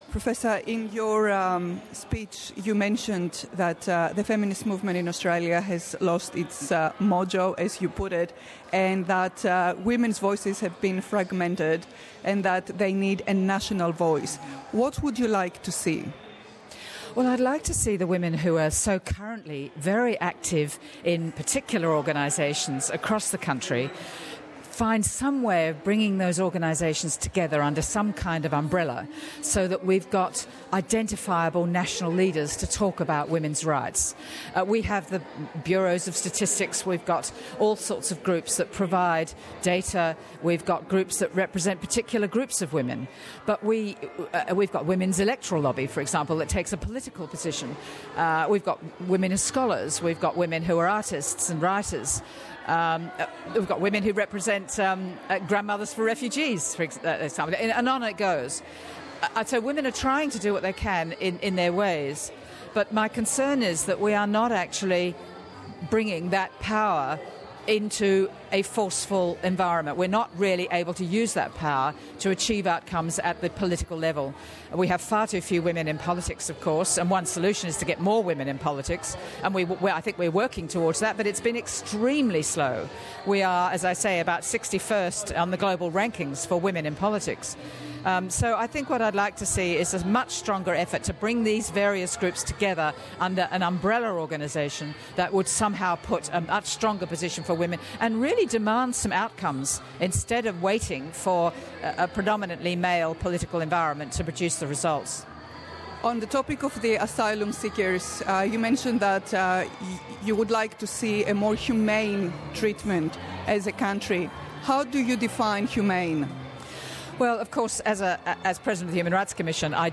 Περισσότερα ακούστε στην συνέντευξη που ακολουθεί.